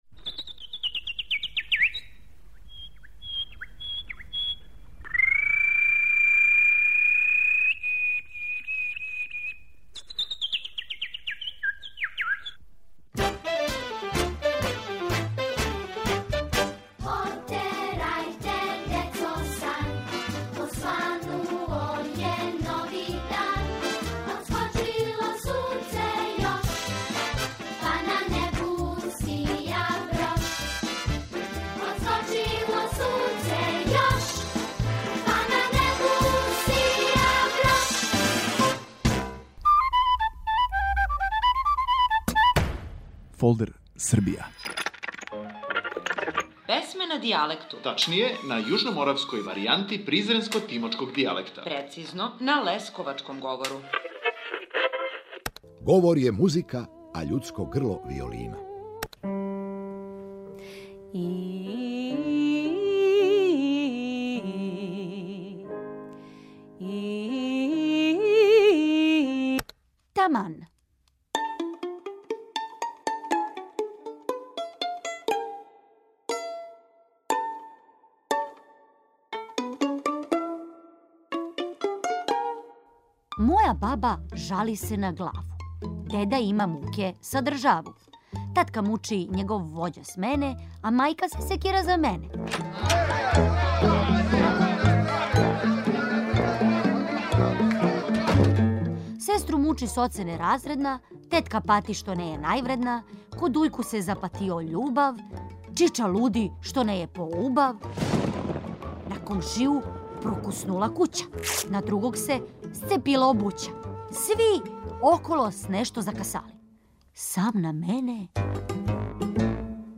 на јужноморавској варијанти призренско - тимочког дијалекта, прецизније - на лесковачком говору.